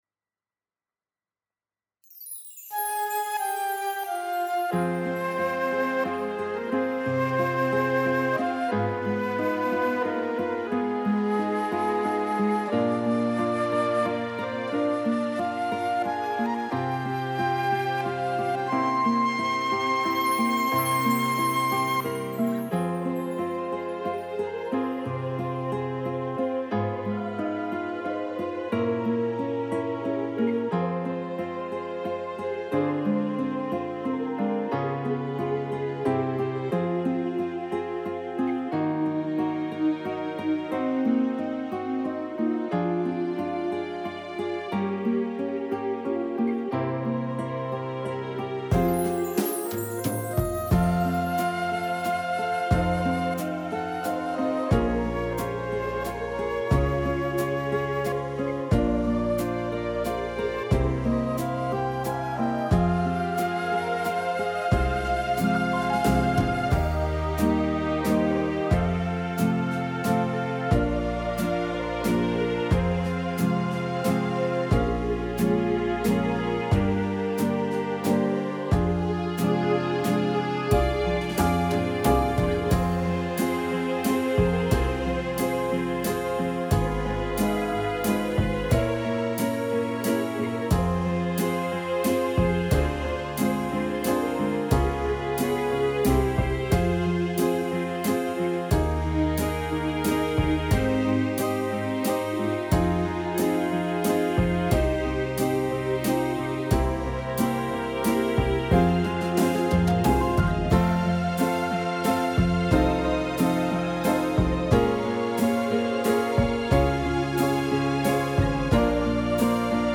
•   Beat  01.
Fm 3:56